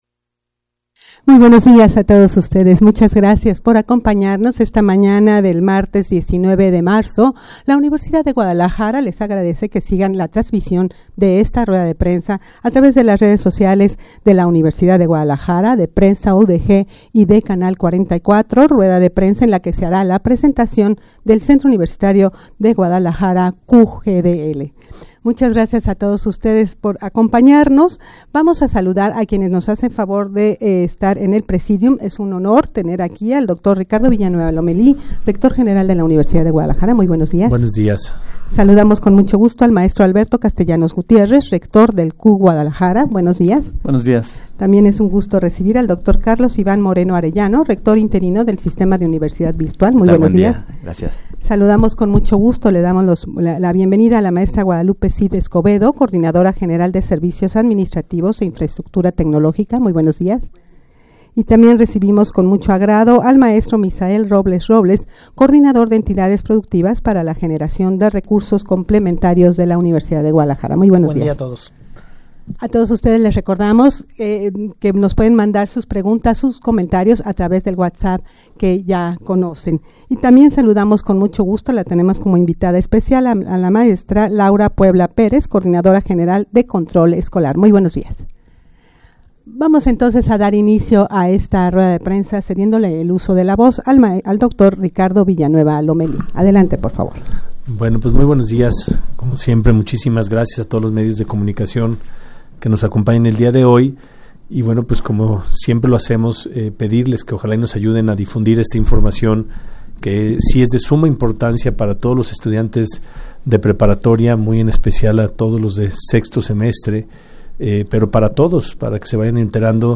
Audio de la Rueda de Prensa
rueda-de-prensa-en-la-que-se-hara-la-presentacion-del-centro-universitario-guadalajara-cugdl.mp3